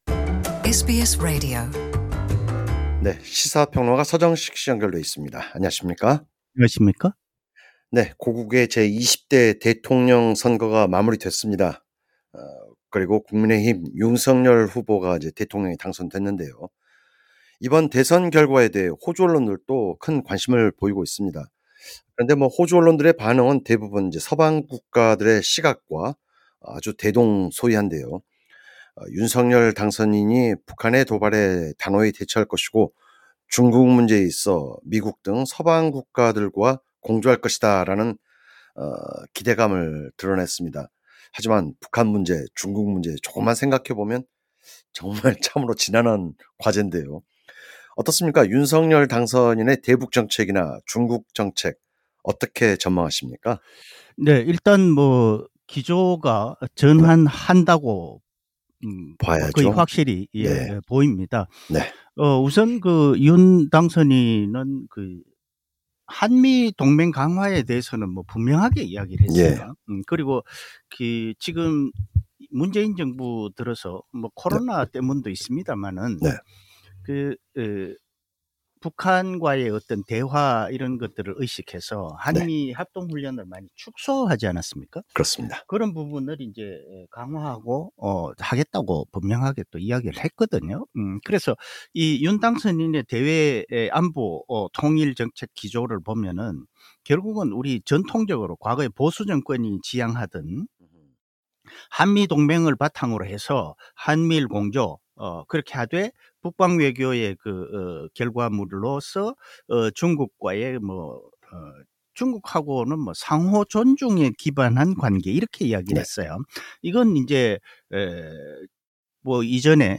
윤석열 당선인의 향후 외교정책을 진단해봅니다. 해설: 시사평론가